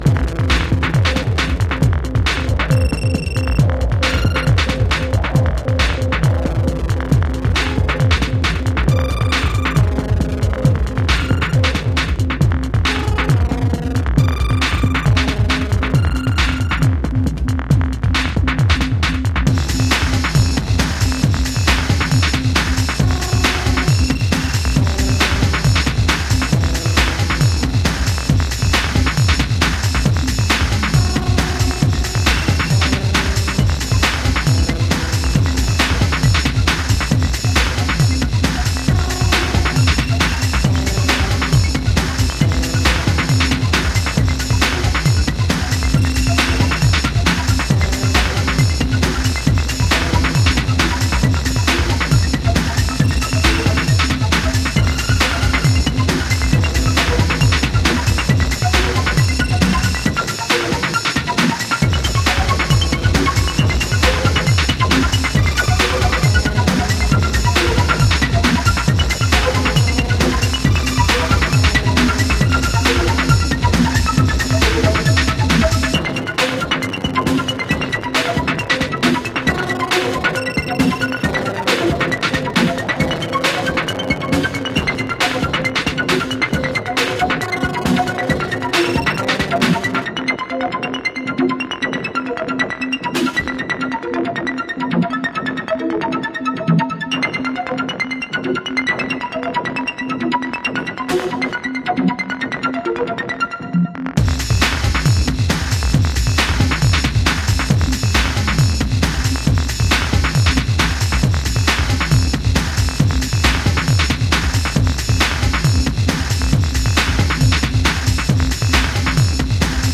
Sub-heavy, UK inspired House EP